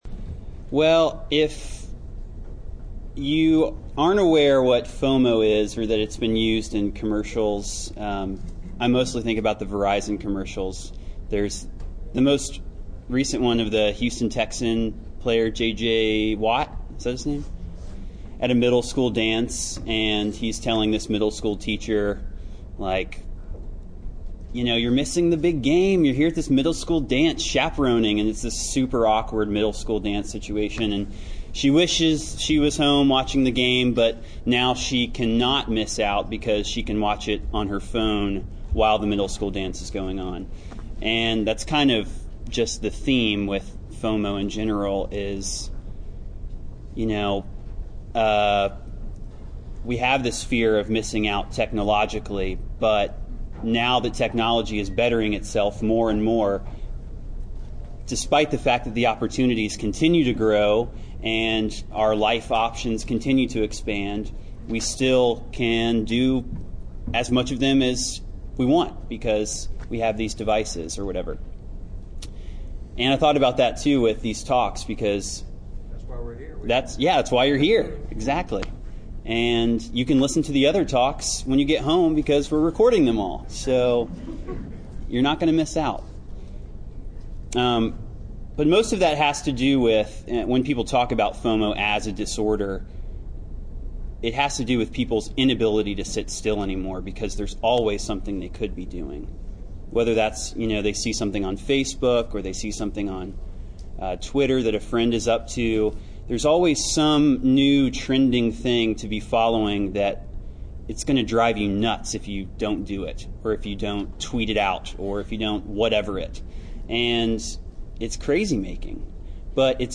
Venue: 2014 Fall Conference